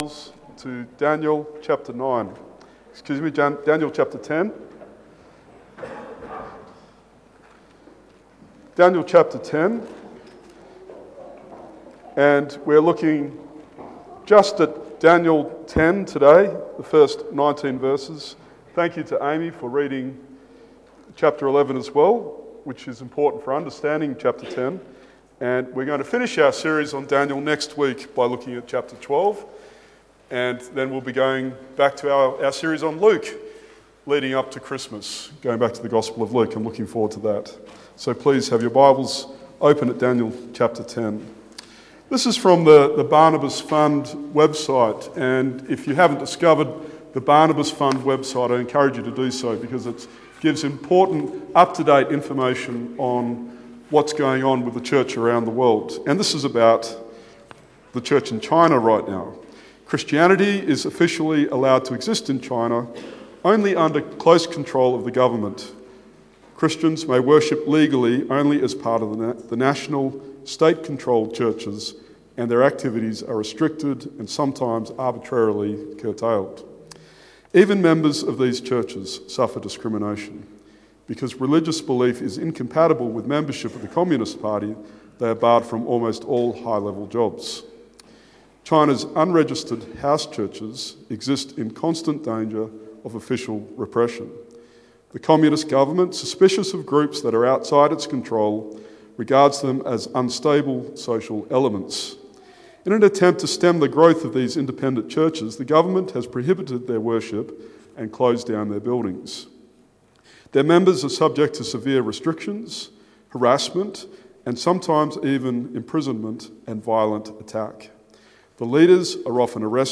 Daniel 10:1-21 Sermon